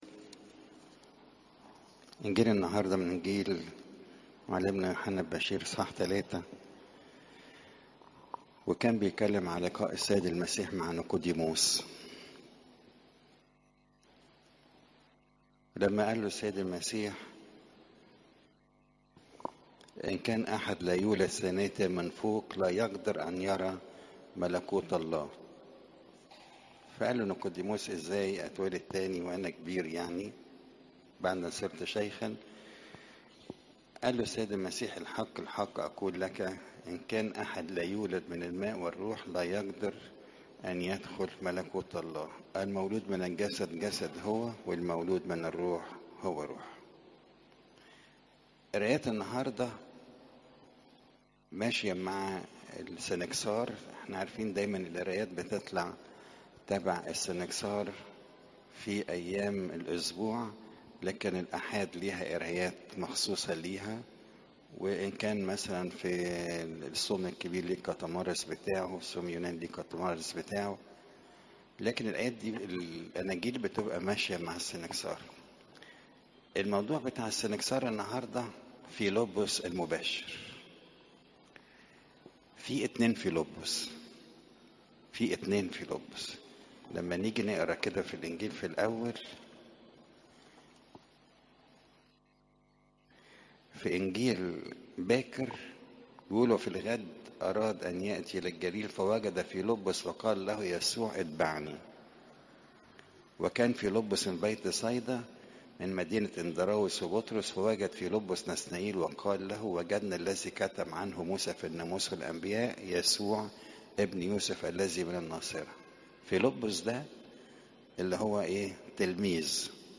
عظات قداسات الكنيسة (يو 3 : 1 - 18)